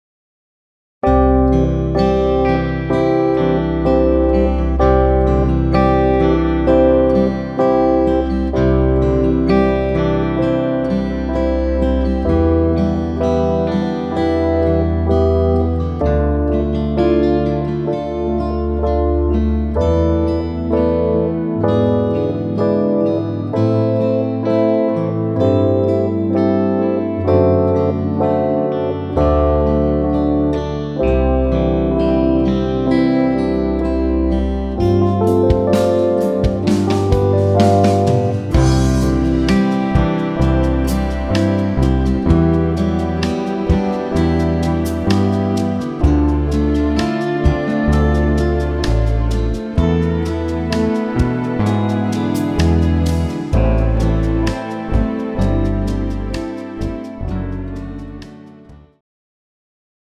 pop-rock ballad style
tempo 64 bpm
male backing track
This backing track is in pop rock ballad style.